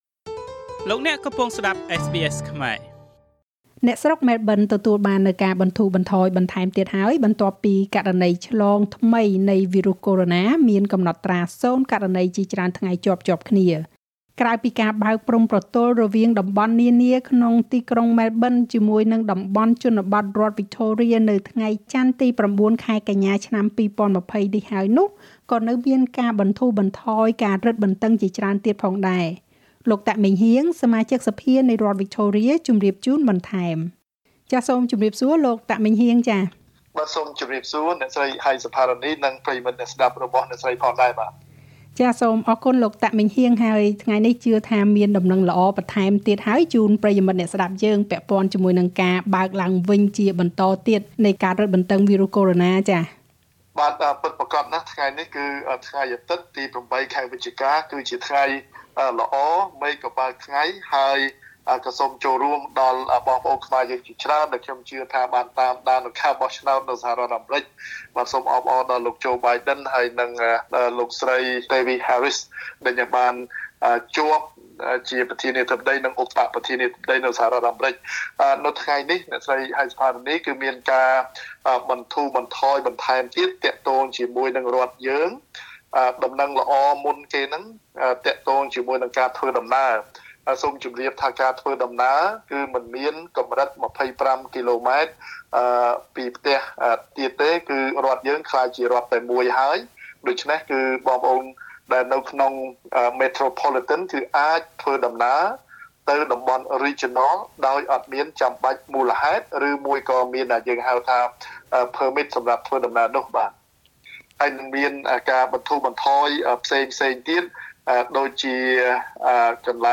អ្នកស្រុកម៉ែលប៊នទទួលបានការបន្ធូរបន្ថយបន្ថែមទៀតបន្ទាប់ពីករណីឆ្លងថ្មីនៃវីរុសកូរ៉ូណាមានកំណត់ត្រា០ករណី ជាច្រើនថ្ងៃជាប់ៗគ្នា។ ក្រៅពីការបើកព្រំប្រទល់រវាងតំបន់នានាក្នុងទីក្រុងម៉ែលប៊ន និងតំបន់ជនរដ្ឋវិចថូរៀហើយនោះ ក៏នៅមានការបន្ធូរបន្ថយការរឹតបន្តឹងជាច្រើនទៀតផងដែរ។ លោកតាក់ម៉េងហ៊ាង សមាជិកសភានៃរដ្ឋវិចថូរៀ ជម្រាបជូនបន្ថែម។